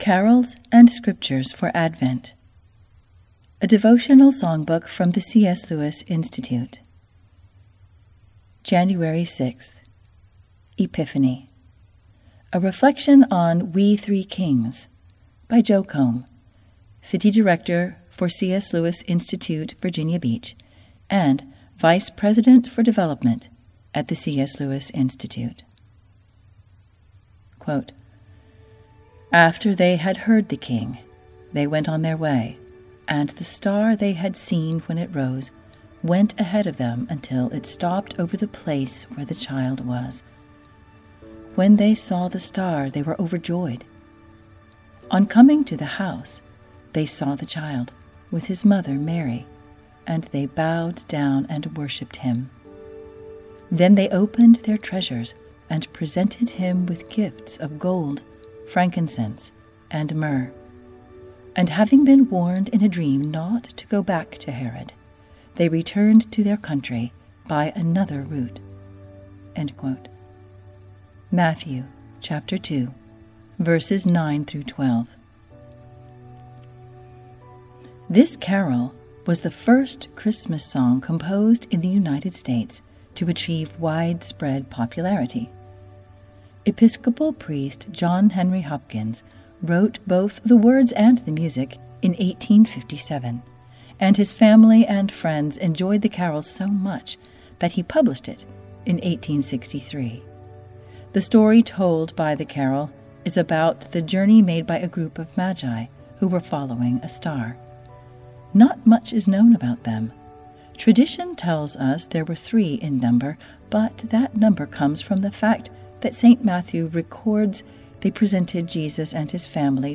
Explore 30 beloved Christmas carols accompanied by original piano recordings. Each carol is paired with a passage of Scripture, a short reflection, and master artwork for the Advent Season.